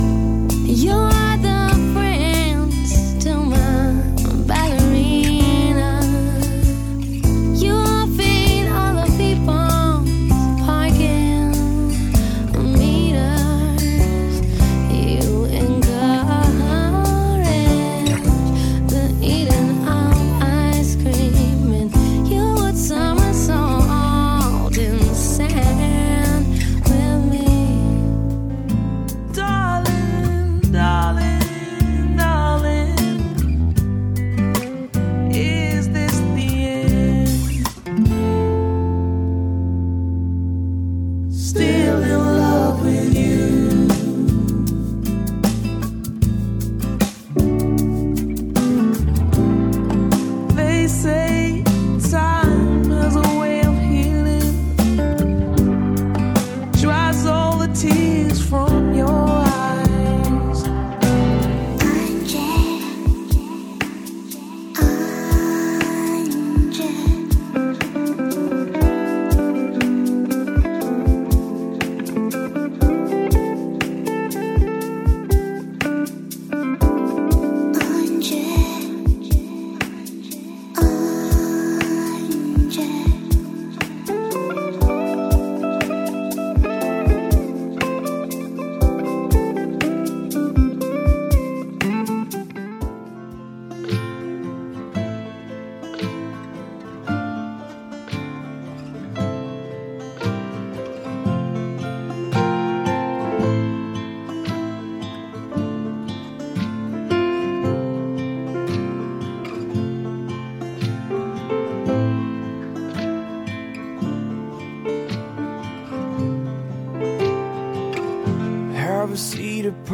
Relaxing Songs
Mellow Songs for a Relaxing Atmosphere